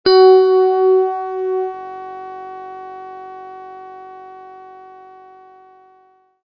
koncovy_loop_aplikovany.mp3